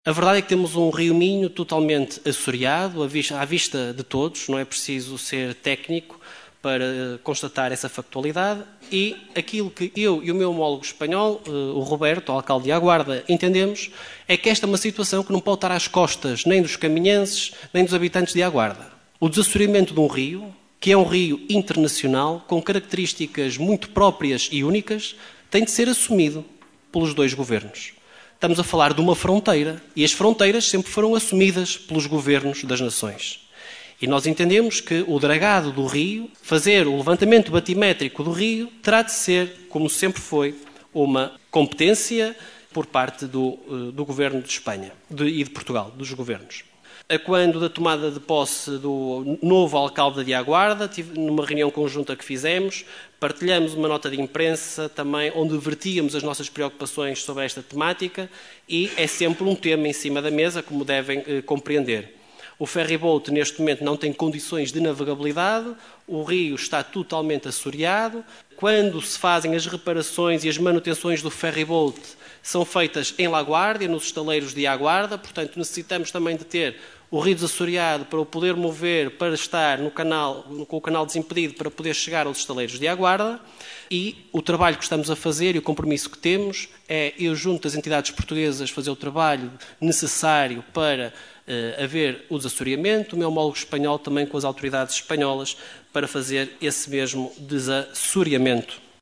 O parque eólico que está projetado para o sul da Galiza, a dívida à Luságua, a questão do CET, o Ferry Boat e o estado “vergonhoso”  em que se encontram as ruas e estradas municipais foram algumas das questões deixadas pelo deputado da coligação O Concelho em Primeiro (OCP) Jorge Nande ao Presidente da Câmara Rui Lages no período antes da ordem do dia da última assembleia municipal de Caminha.